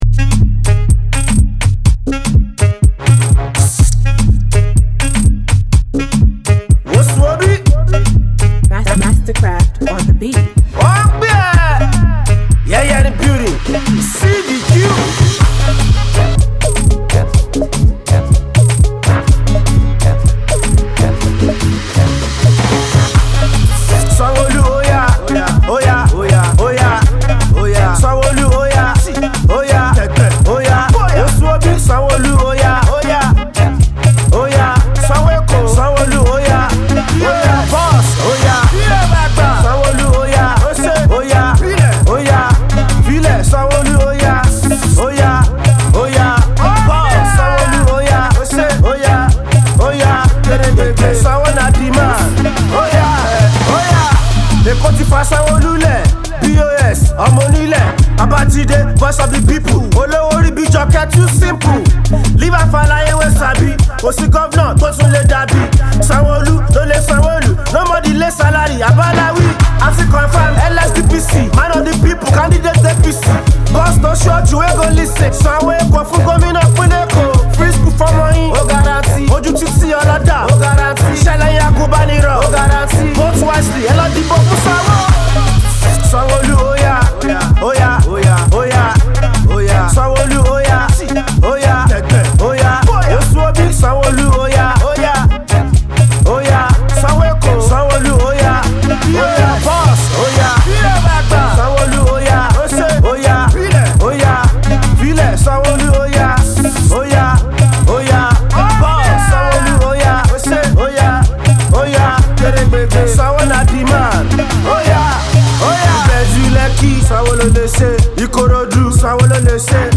dance tune